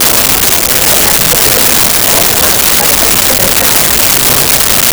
Bar Crowd Loop 01
Bar Crowd Loop 01.wav